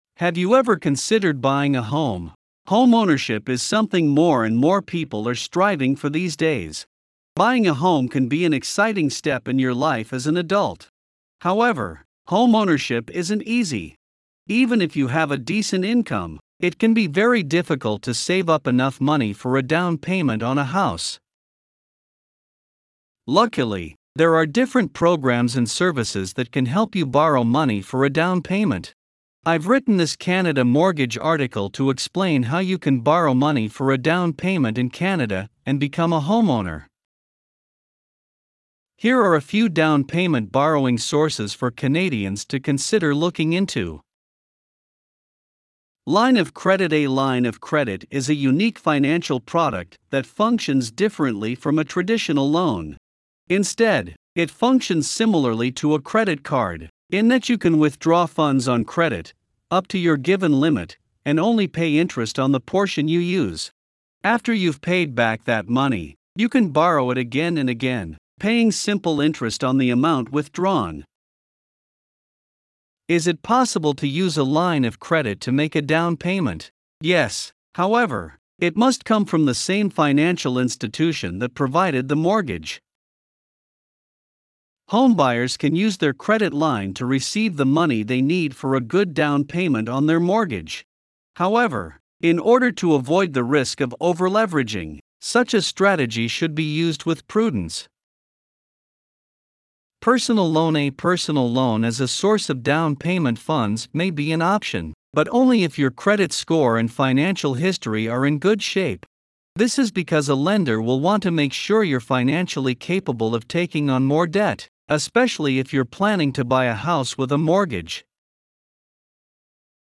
Voiceovers-Voices-by-Listnr_3.mp3